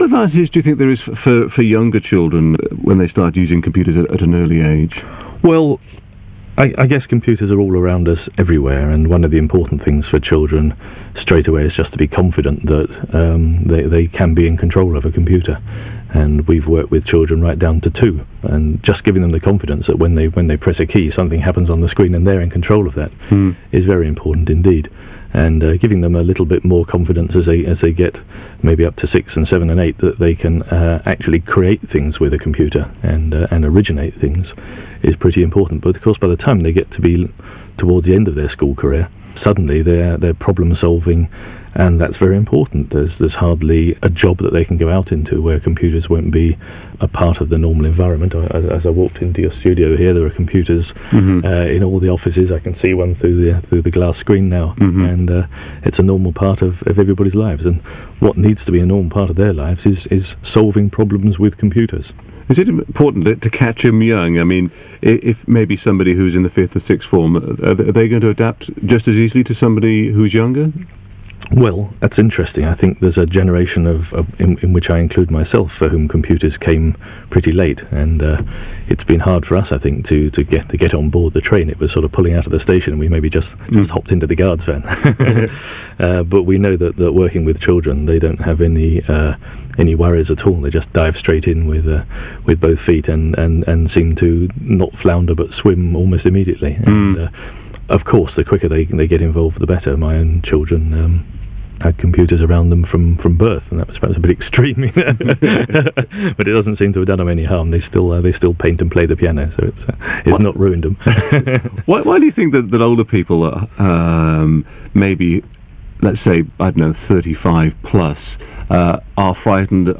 this is what I said in reply (you can here the whole interview from here). In short, back in those days I often said the moment that a baby is able to roll onto a keyboard and notice that something has happened as a result is the time to start thinking about finding a way to get them a computer and thinking about what they might do woth it that is appropriate. 20 years on, I haven't changed that advice, but now we have phones too and the cycle is repeating.